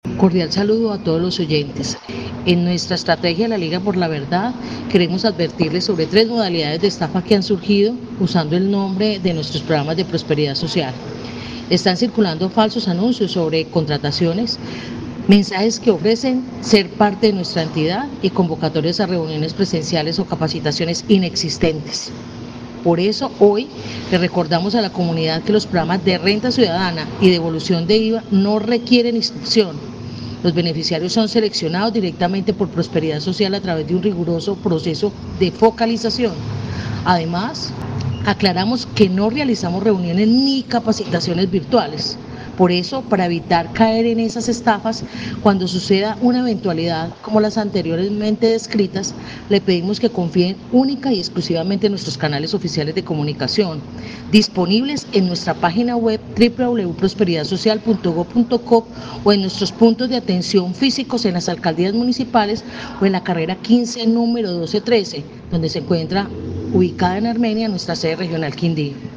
Directora DPS en el Quindío, Luz Helena Forero